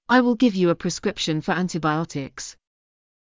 ｱｲ ｳｨﾙ ﾌﾟﾘｽｸﾗｲﾌﾞ ｱ ﾌﾟﾚｽｸﾘﾌﾟｼｮﾝ ﾌｫｰ ｱﾝﾃｨﾊﾞｲｵﾃｨｯｸｽ